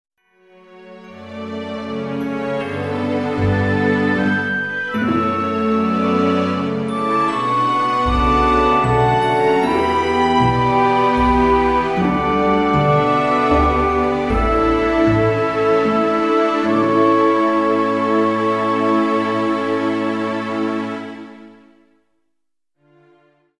弦のアンサンブルをメインにゆったりと静かなヒーリングサウンド。 フルートやグロッケンも交えた小編成の楽曲。